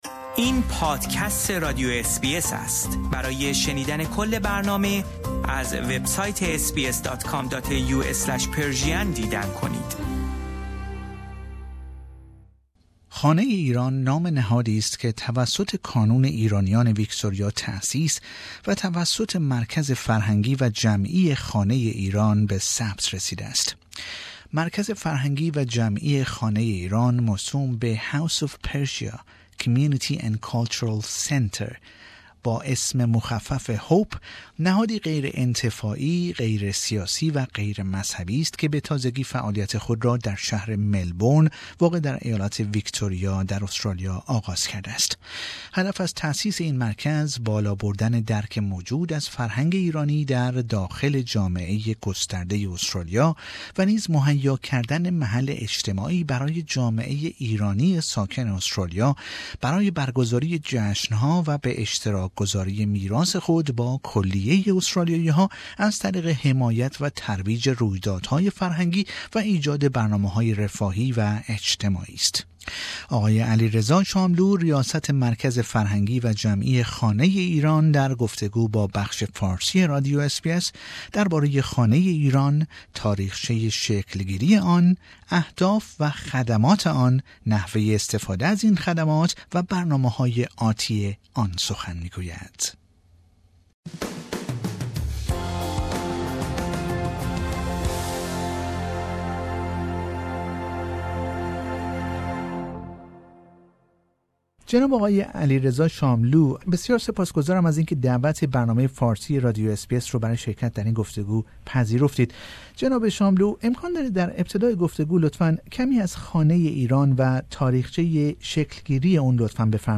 در گفتگو با بخش فارسی رادیو اس بی اس درباره خانه ایران، تاریخچه شکل گیری آن، اهداف و خدمات، نحوه استفاده از این خدمات و برنامه های آتی آن سخن می گوید.